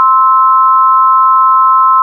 Middle C - 261.63 Hz, complex tone, 4th & 5th partials
The pitch sensation for all 3 of these sounds should be that of middle C. Even though the last sound does not even contain the frequency associated with middle C (261.63 Hz) the sensation should still be that of middle C. The 4th partial (harmonic) is a sine wave at 4 x 261.63 Hz = 1,046.5 Hz. The 5th partial (harmonic) is a sine wave at 5 x 261.63 Hz = 1,308.2 Hz.